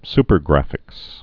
(spər-grăfĭks)